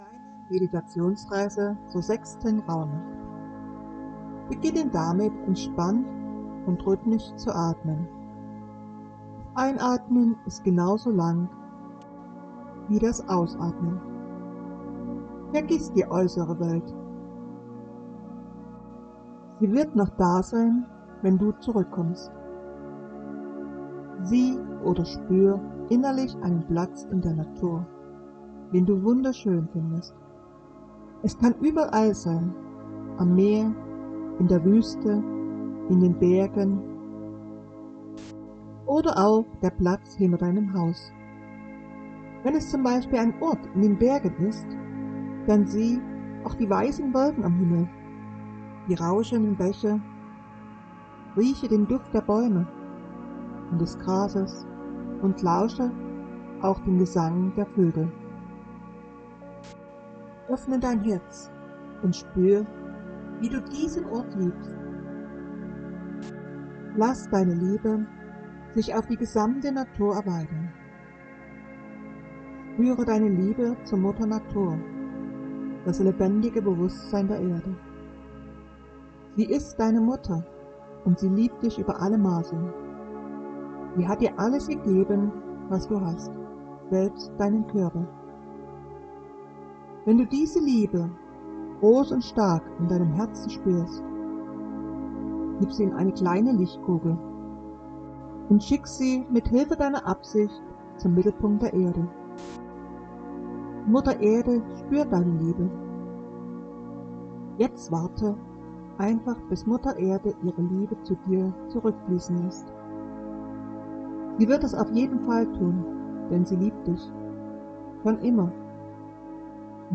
Die unterlegte Musik ist eine 852 Hz Liebesfrequenz zur Erhöhung deiner Energievibration. Die Musik eignet sich sehr gut für tiefe Meditationen und bringt dich auf ein hohes Schwingungsniveau.